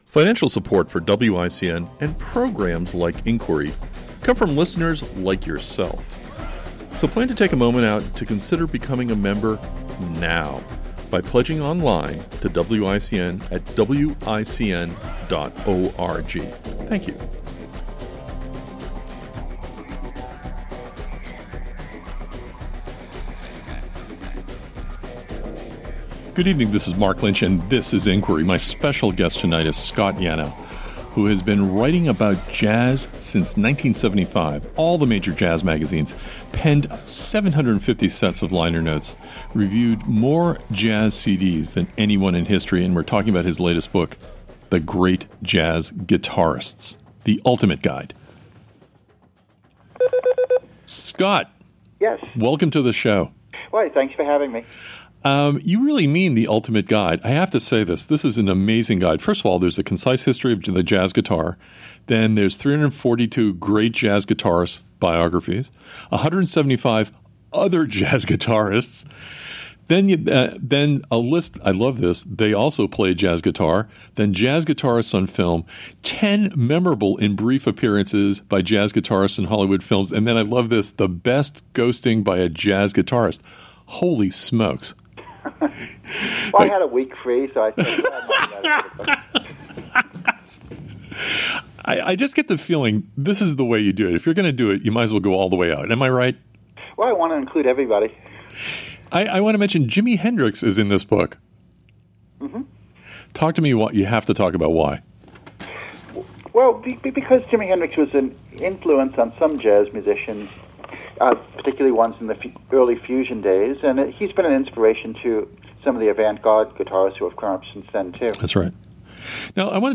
Click to hear a recent interview I did about my most recent book. Jazz Guitarists Interview